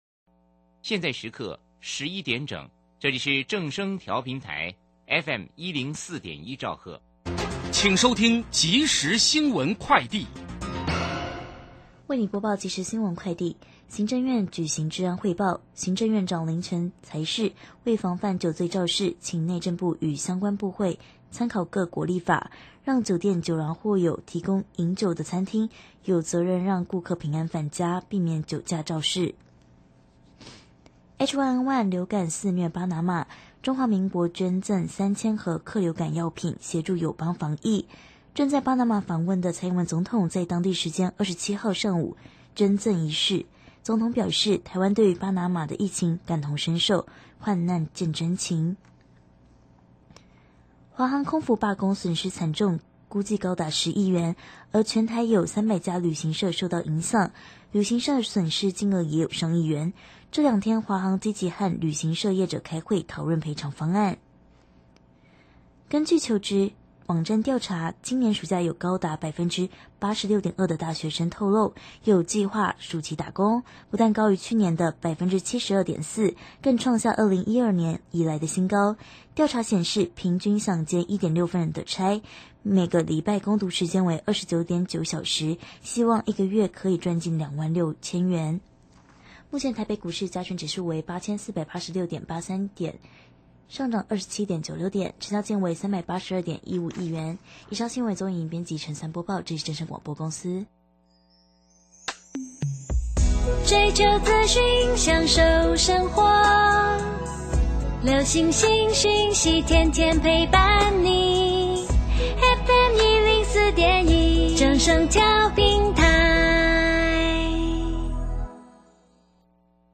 受訪者： 台北地檢申心蓓檢察官 節目內容： 明明這個車禍就是對方來撞我的，因為沒受傷就離開，是否構成肇事逃逸罪